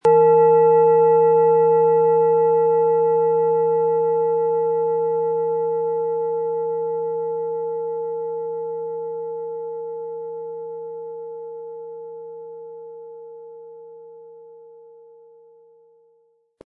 Tibetische Universal-Klangschale, Ø 15,8 cm, 400-500 Gramm, mit Klöppel
Wir haben ebendiese Klangschale beim Aufnehmen angespielt und das persönliche Empfinden, dass sie alle Körperregionen gleich deutlich zum Schwingen bringt.
Mit dem beiliegenden Klöppel wird Ihre Klangschale schöne Töne von sich geben.
MaterialBronze